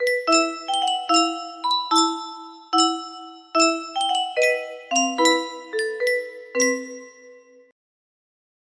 3107 music box melody
Grand Illusions 30 (F scale)